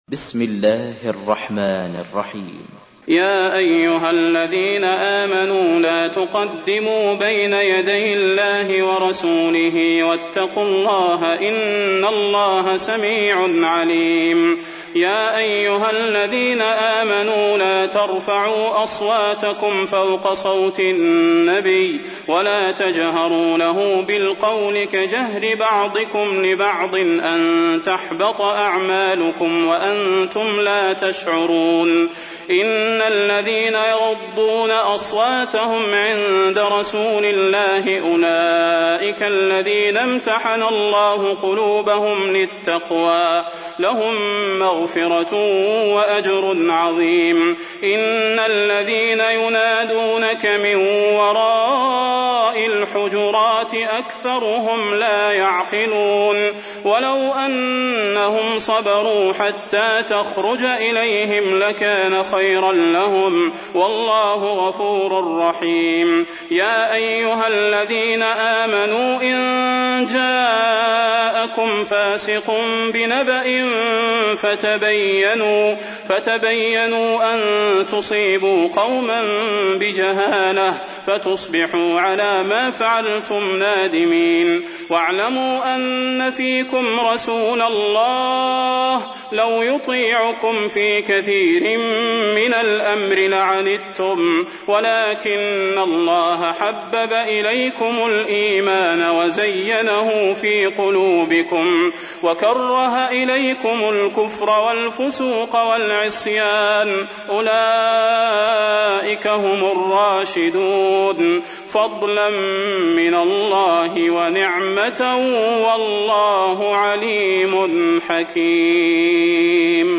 تحميل : 49. سورة الحجرات / القارئ صلاح البدير / القرآن الكريم / موقع يا حسين